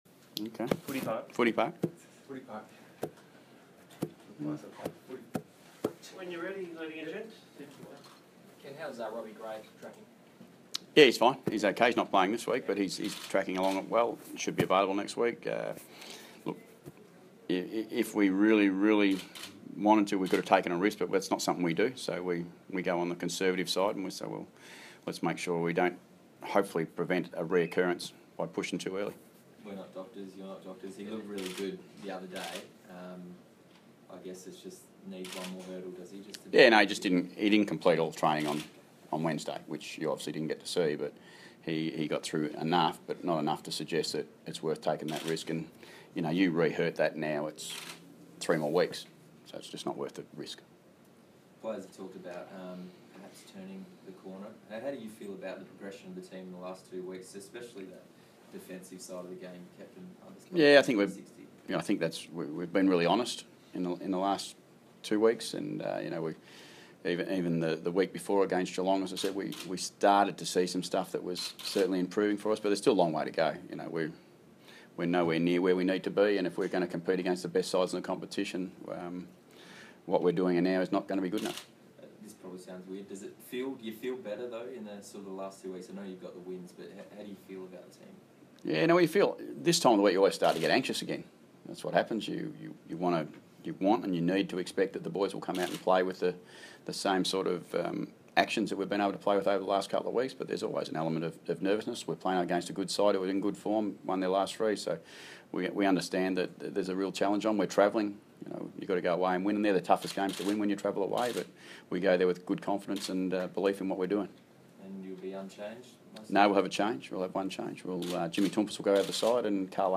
Ken Hinkley Press Conference - Friday, 13 May, 2016